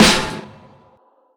Livingz_snr.wav